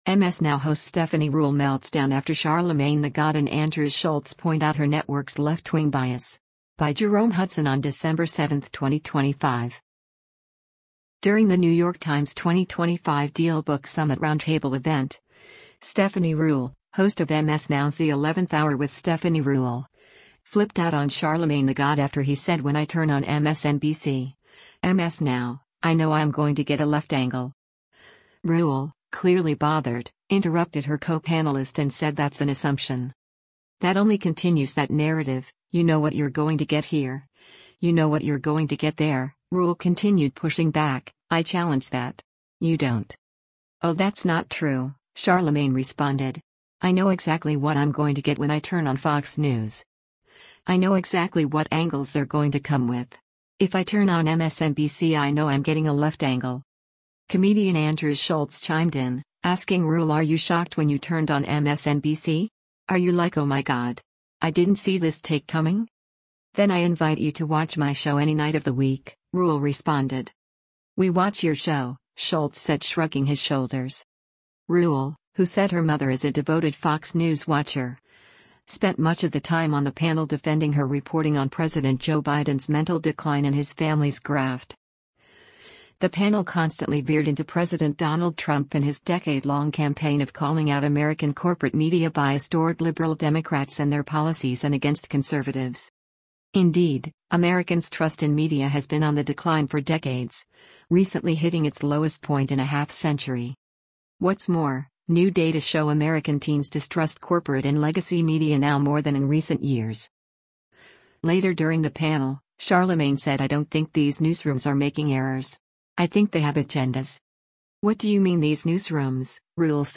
During the New York Times’ 2025 Dealbook Summit roundtable event, Stephanie Ruhle, host of MS NOW’s The 11th Hour With Stephanie Ruhle, flipped out on Charlamagne the God after he said “when I turn on MSNBC [MS NOW] I know I’m going to get a left angle.” Ruhle, clearly bothered, interrupted her co-panelist and said “that’s an assumption.”